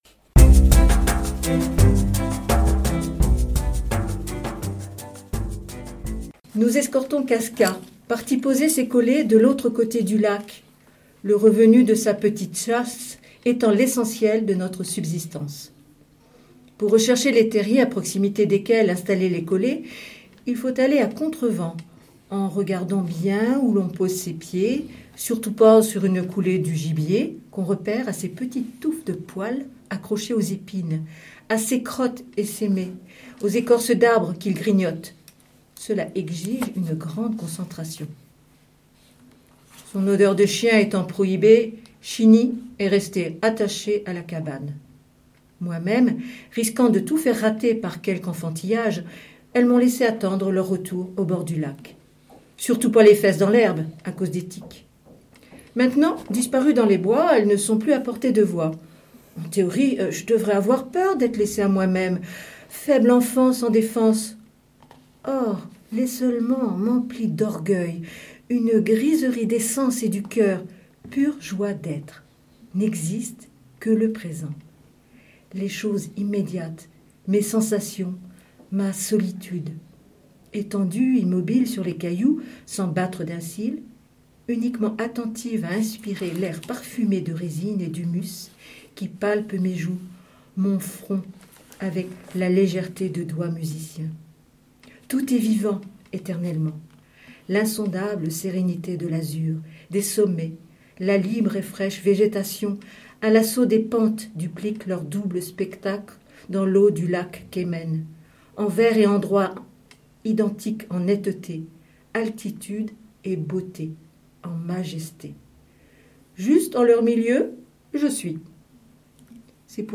Les hauts-parleurs de l'association " Lire à Saint-Lô " nous lisent des extraits de livres dans l'émission "Lire et délire"